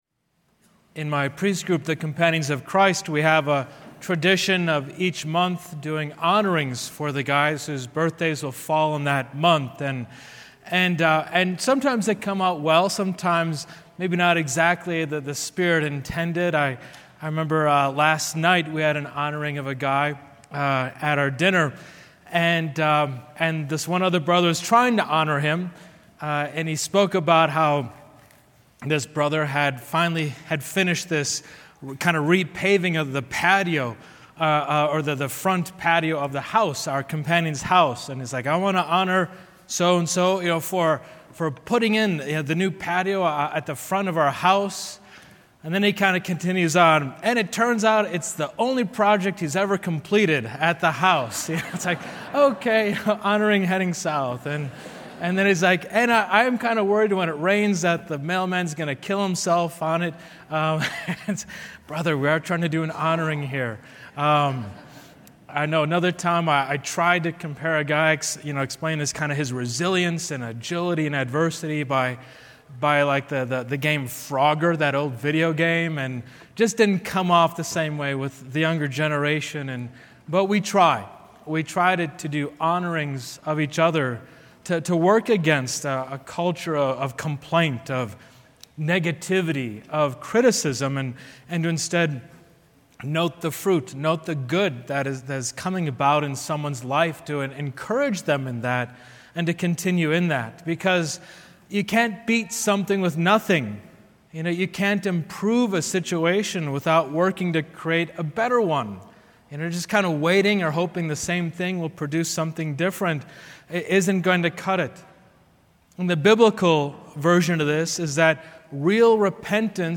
25 Mar 3rd Sunday of Lent Homily